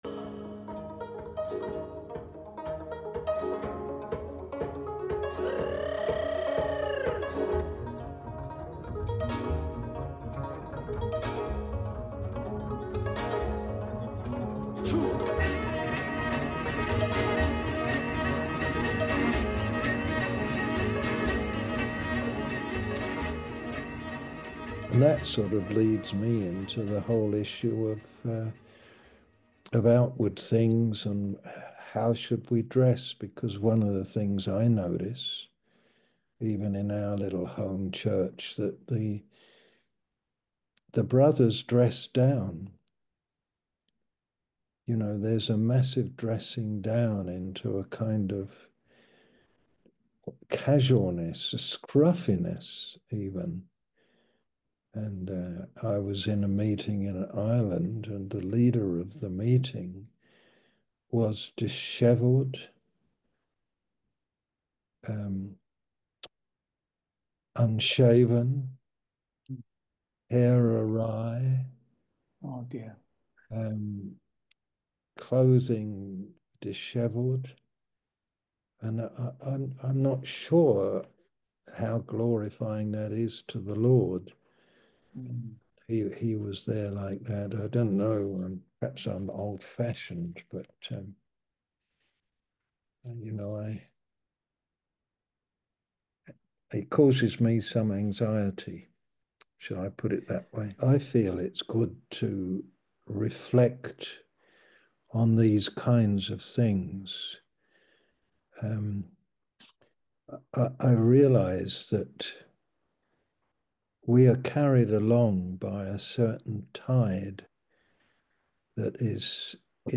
A message from the series "US Mens Meetings."